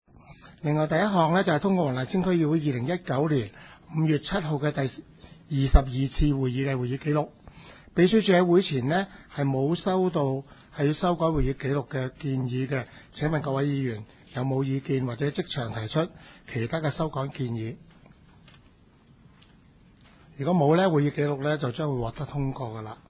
区议会大会的录音记录
黄大仙区议会第二十三次会议
黄大仙区议会会议室